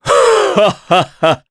Gau-Vox-Laugh_jp.wav